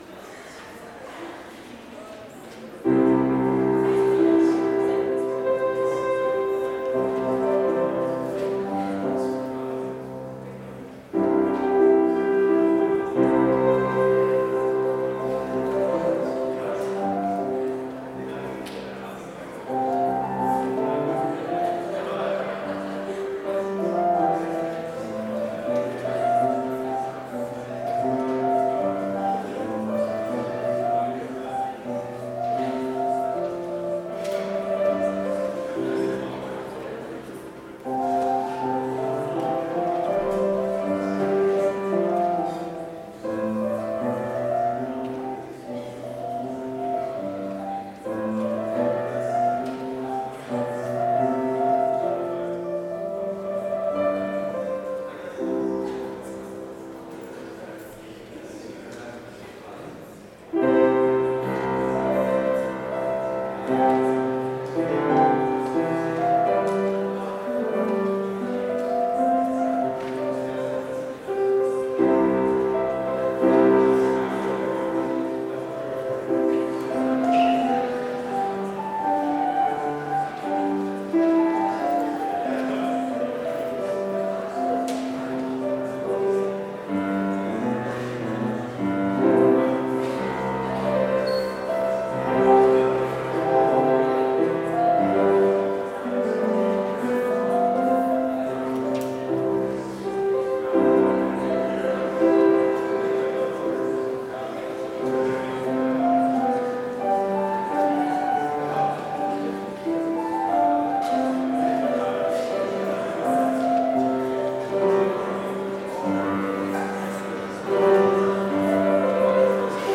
Complete service audio for Chapel - January 21, 2022